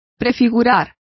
Complete with pronunciation of the translation of foreshadowed.